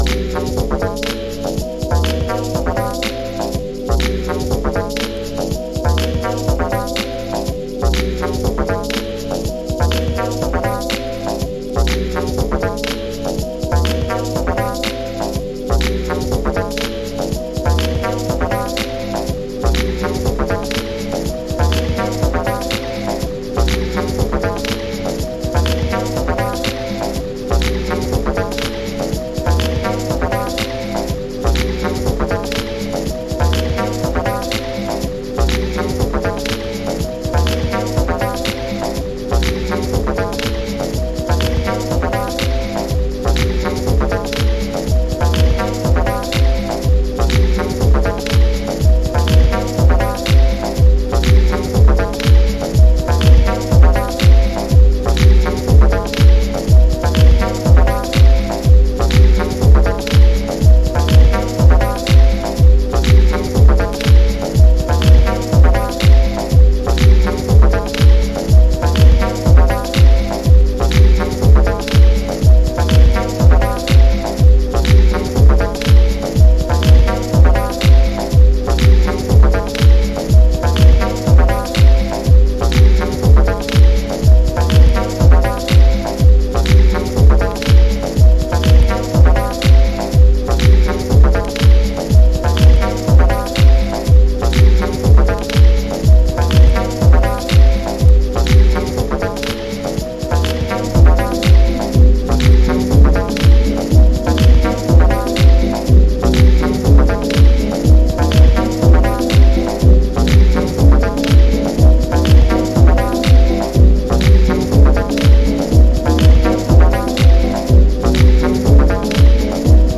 ビートダウンしたビートにミニマルなシンセで物語ります。